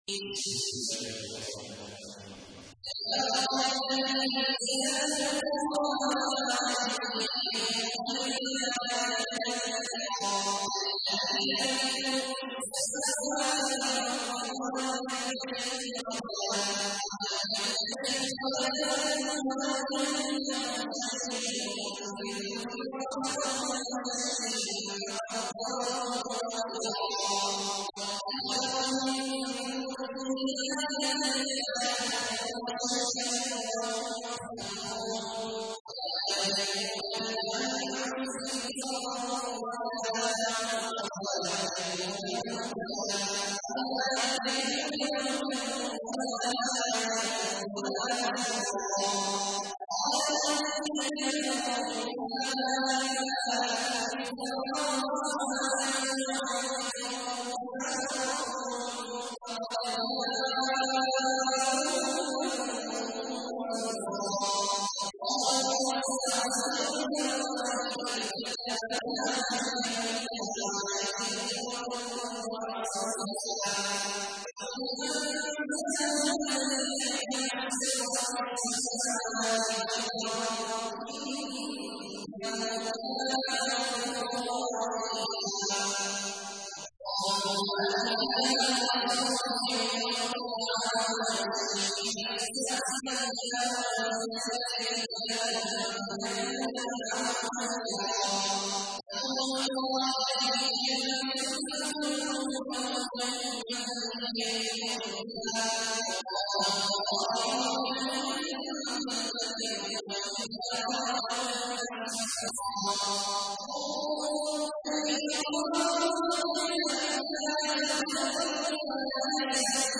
تحميل : 25. سورة الفرقان / القارئ عبد الله عواد الجهني / القرآن الكريم / موقع يا حسين